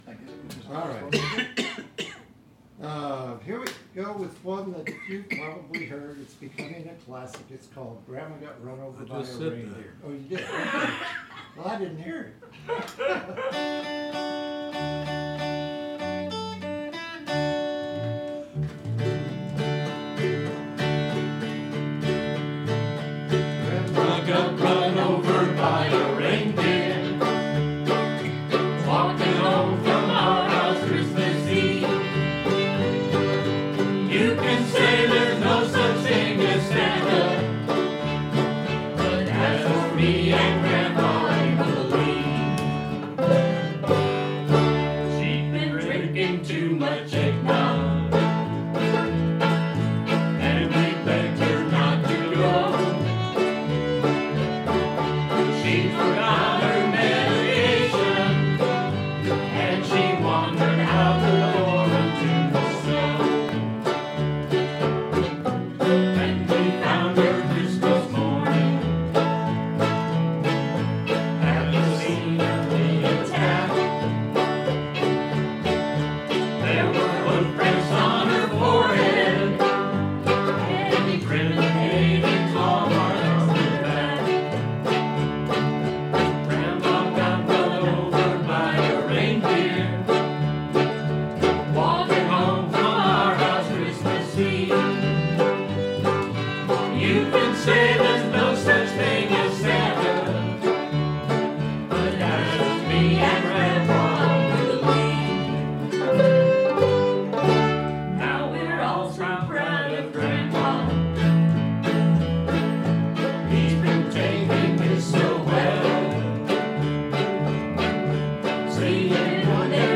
Practice track